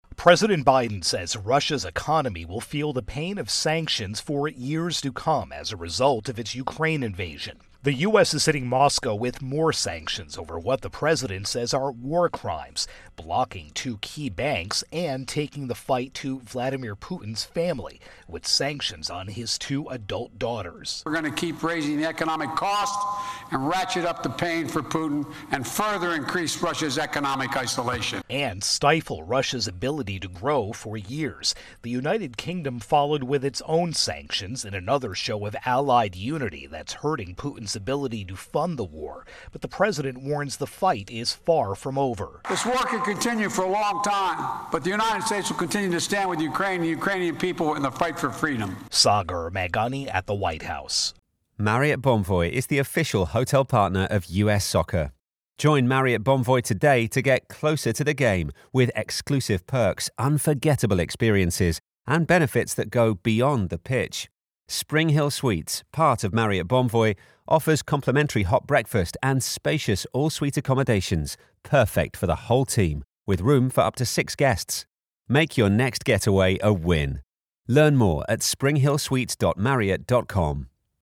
Russia-Ukraine War-Sanctions intro and wrap.